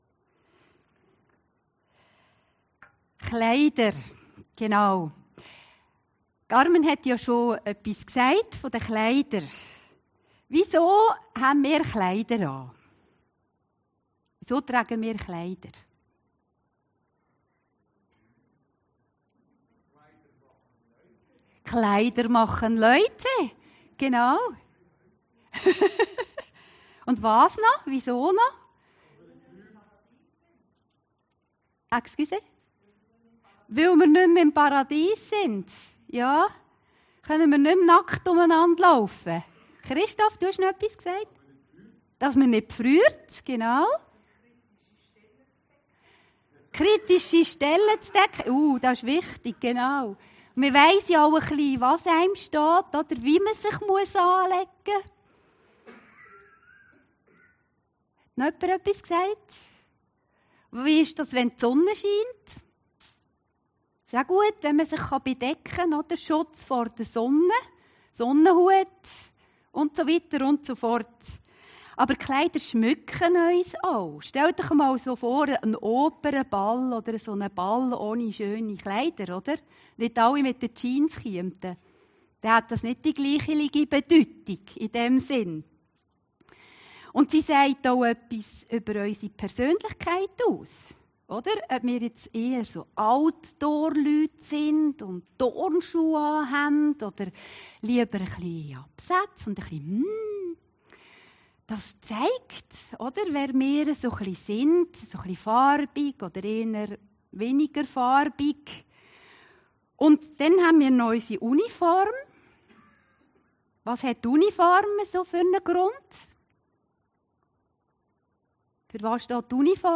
Predigten Heilsarmee Aargau Süd – Sehnsucht nach der himmlischen Heimat